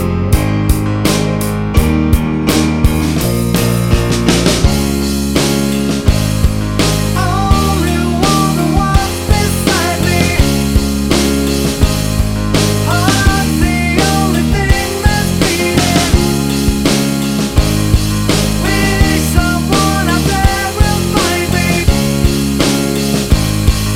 No Guitars or Backing Vocals Rock 4:23 Buy £1.50